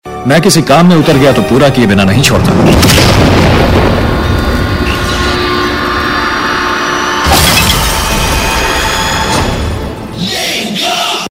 Bollywood Dialogue Tones